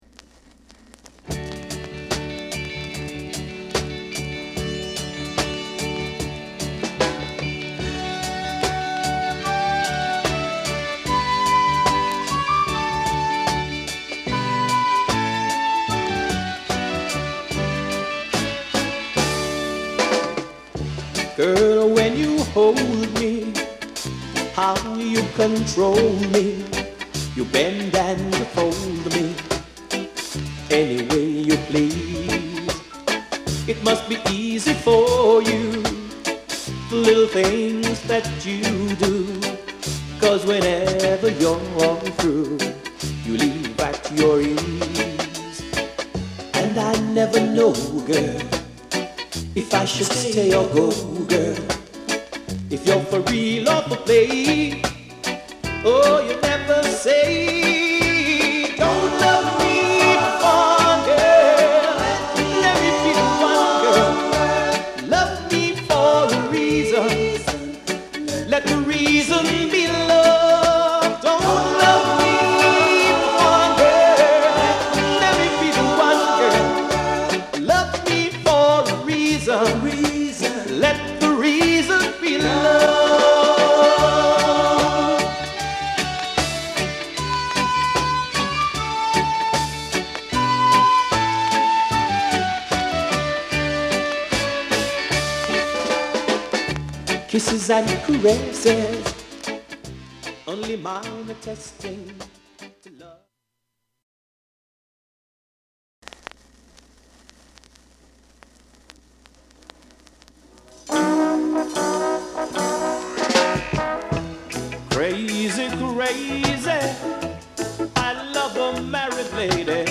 Genre: Reggae / Soul Reggae